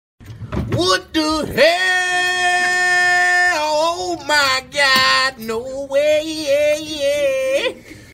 Meme Sound Effect